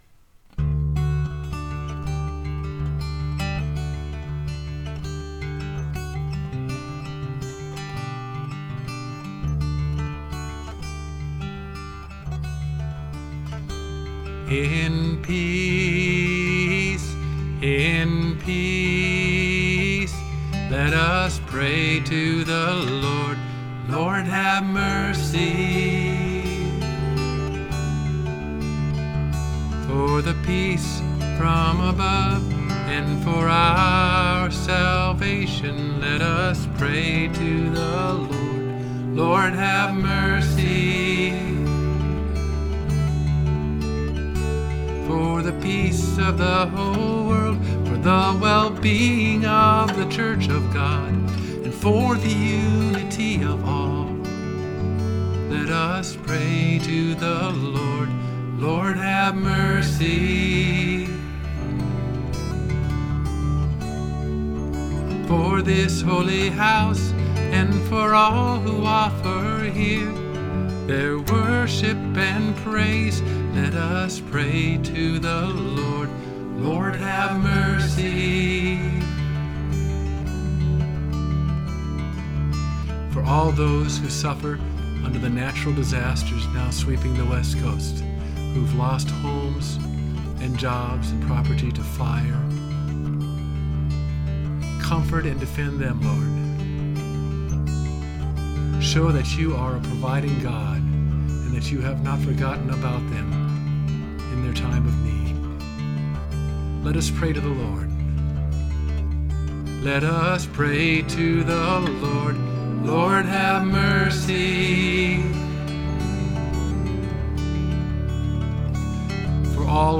MP3 With pastoral prayer and additional Our Father segue
More recently we've begun using this regularly in our service's Time of Prayer: we insert an extra instrumental time where the officiating pastor can speak a couple of petitions.